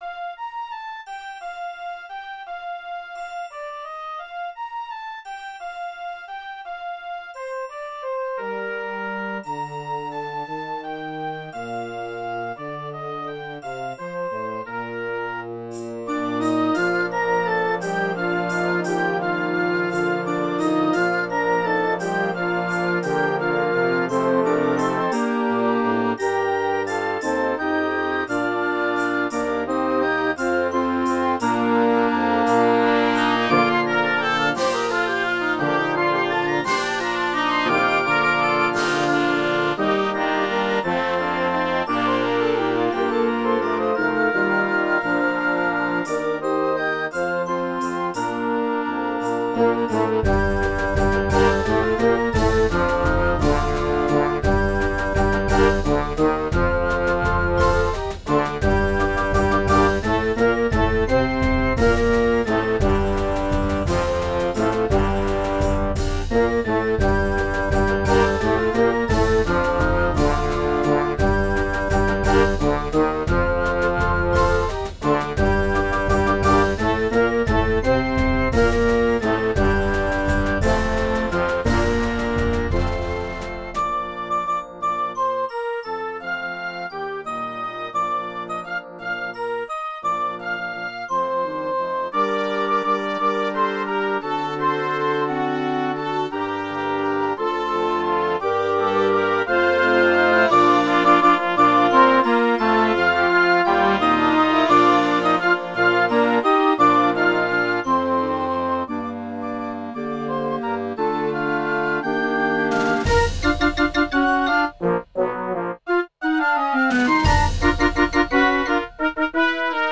Genre: Band
in a lilting, traditional 6/8 Celtic style